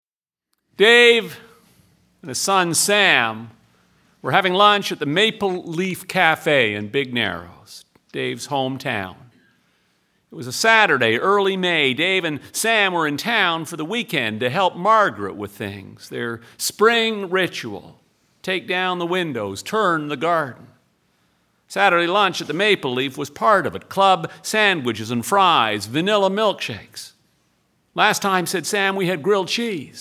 From Stuart McLean's 2014 release called Vinyl Cafe - Auto Pack, here's the single story called "Fish Head".